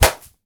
punch_blocked_02.wav